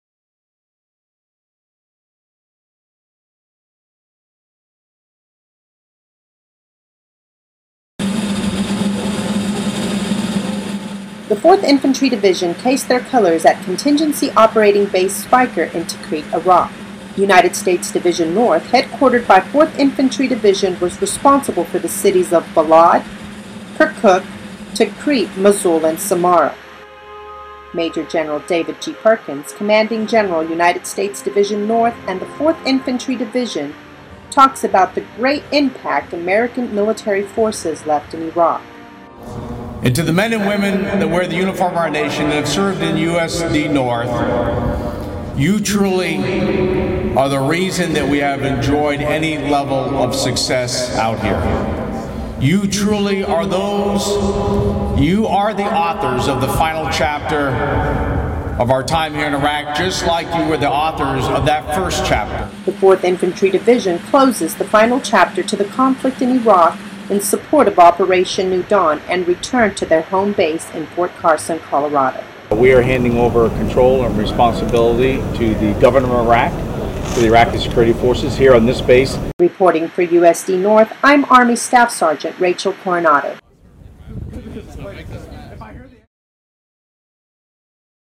Radio package - 4th Infantry Division case their colors...